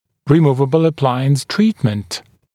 [rɪ’muːvəbl ə’plaɪəns ‘triːtmənt][ри’му:вэбл э’плайэнс ‘три:тмэнт]лечение с применением съемных аппаратов